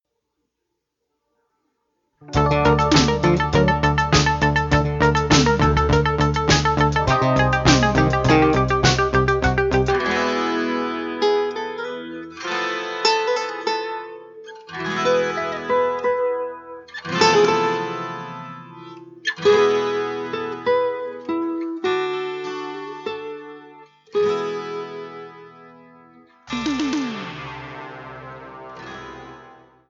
UPC: Soundtrack